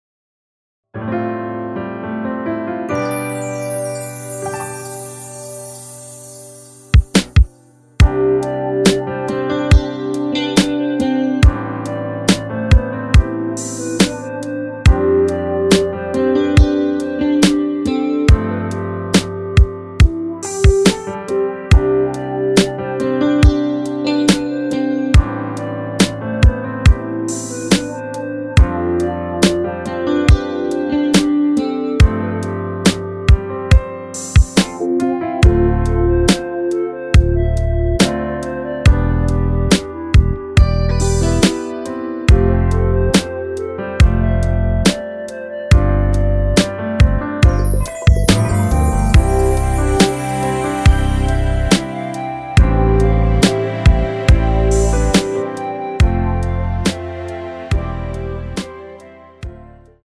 원키에서(+1)올린 멜로디 포함된 MR입니다.
앞부분30초, 뒷부분30초씩 편집해서 올려 드리고 있습니다.
중간에 음이 끈어지고 다시 나오는 이유는